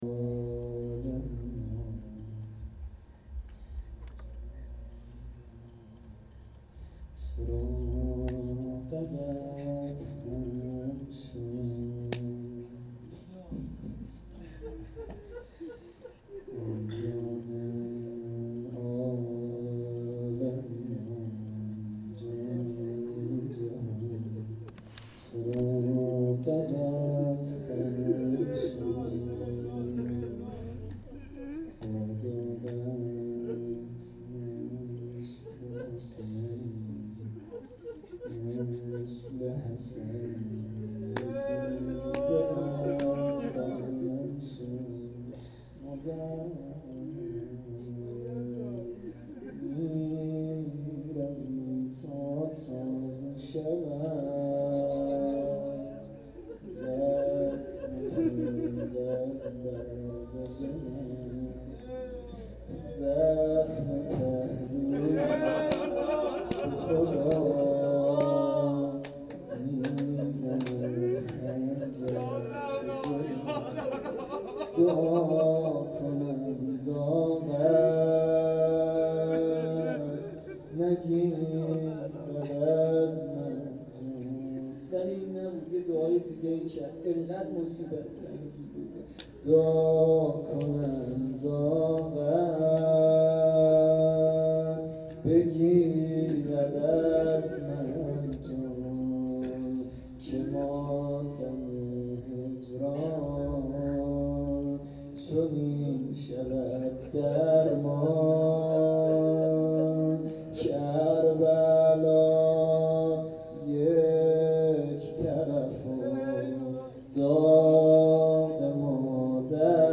روضه شب اول فاطمیه دوم